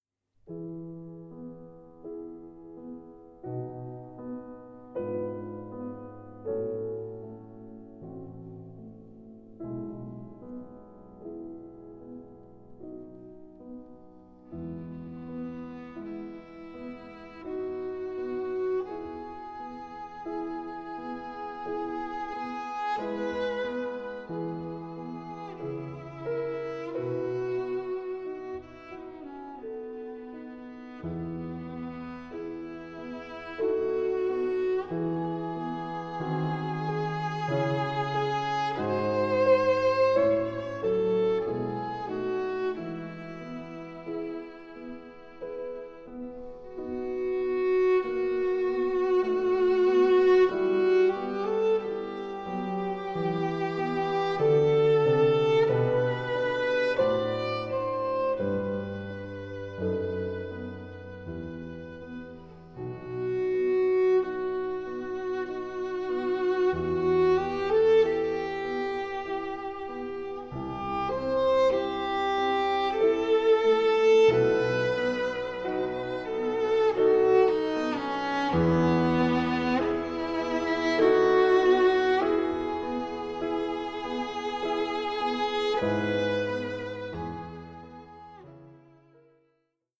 Cello
Klavier